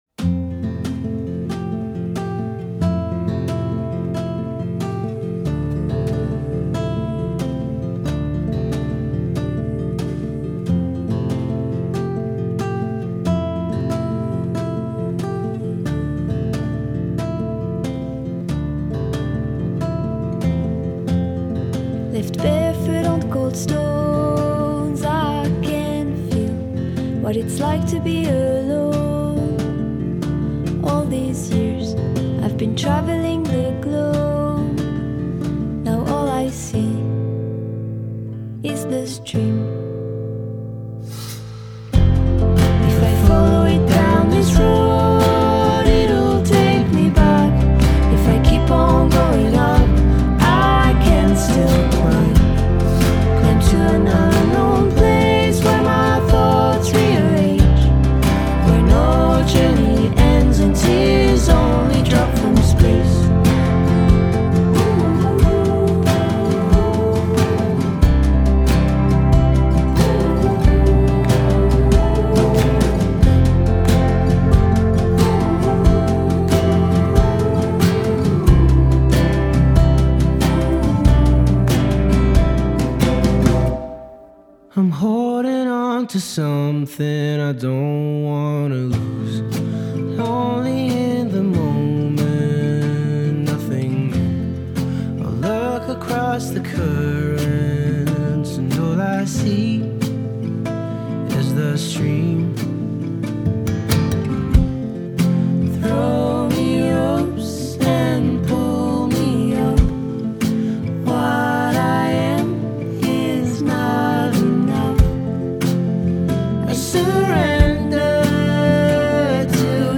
à l’aide de riffs puissants et d’un univers terrien